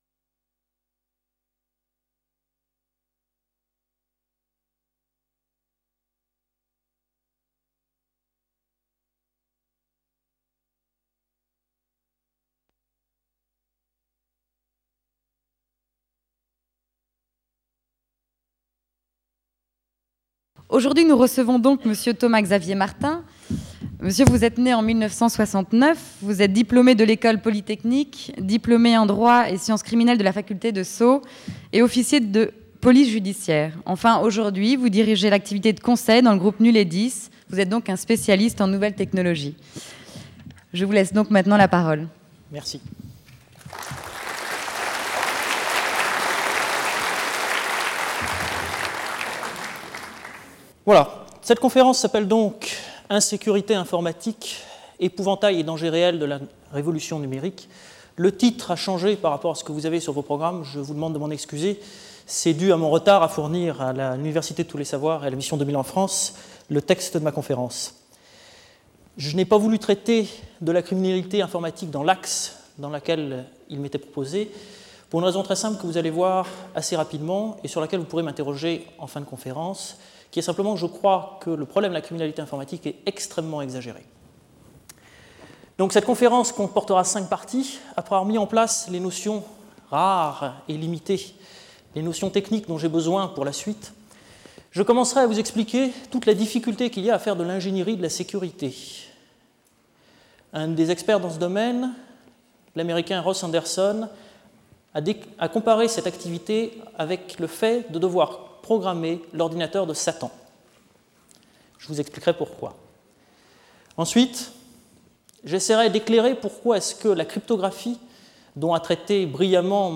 Cette conférence essaiera de jeter un regard original sur certains aspects de la révolution numérique : plutôt que de tenir un discours cent fois entendu sur les dangers du piratage et sur la criminalité informatique, je vais tenter, avec un regard d'ingénieur, de matérialiser les enjeux et les dangers véritables du tout-numérique.